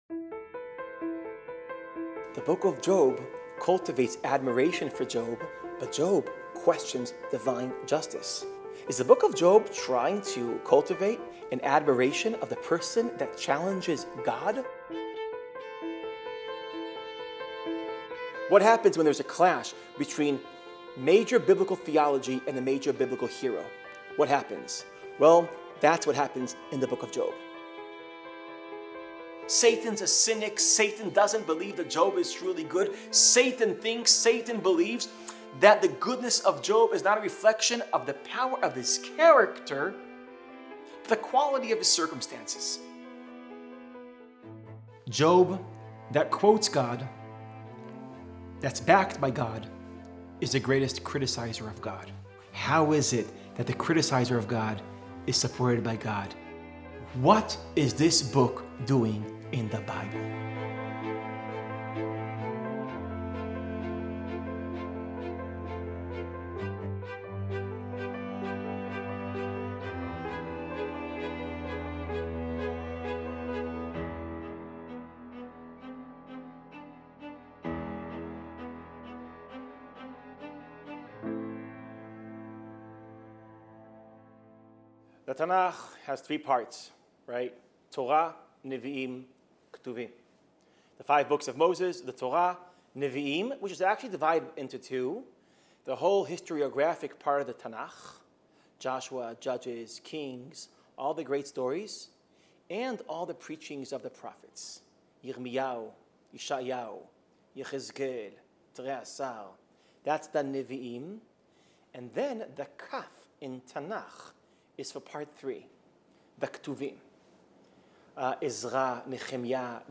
In his opening lecture